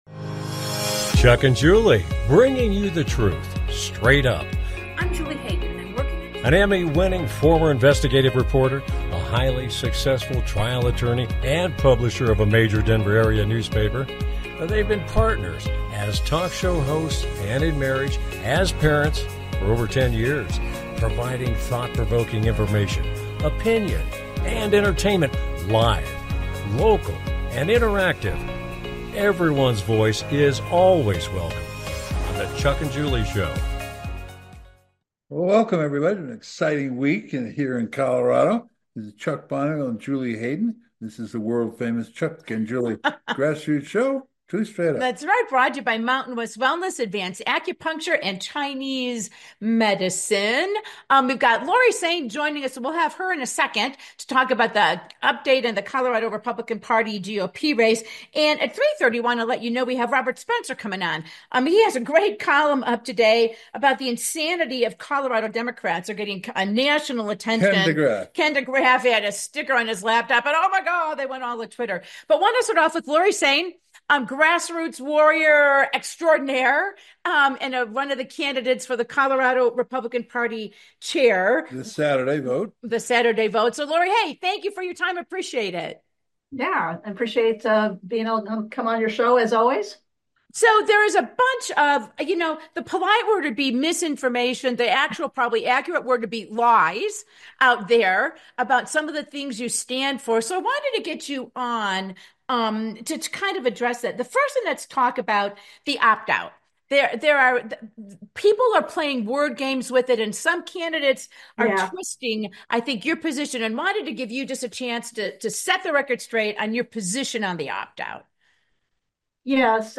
Talk Show Episode
Colorado Democrats prove they hate the Constitution as they go berserk over a Second Amendment sticker on a laptop. Political analyst Robert Spencer reacts to that.